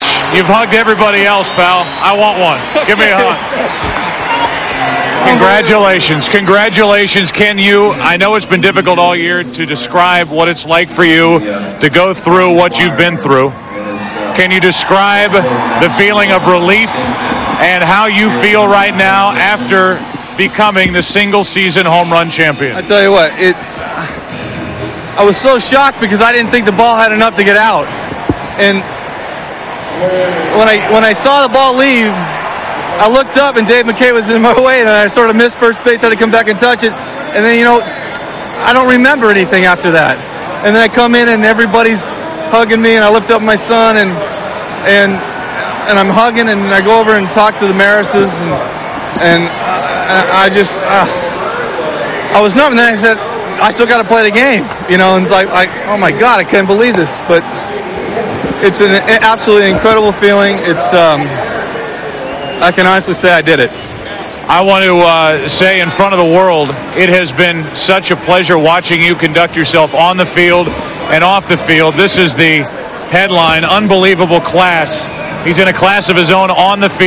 RA Audio: 62nd Home Run Interview 1 - 09 AUG 1999